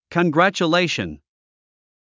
発音 kənɡræ`tʃuléiʃən コングラァチュレイション